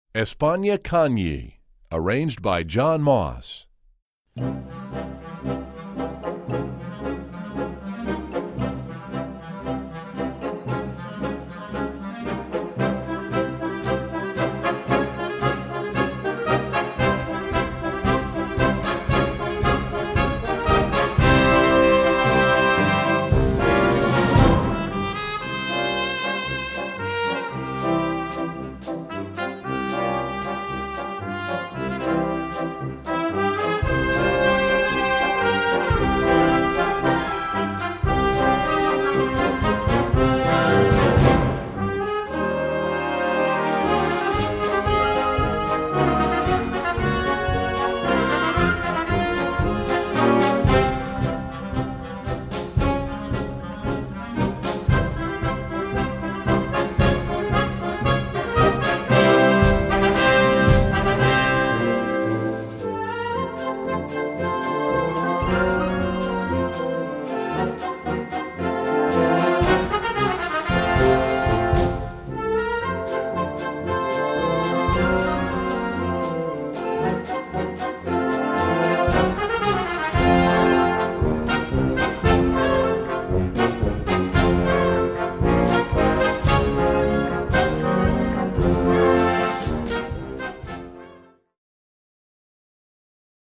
Voicing: Flex March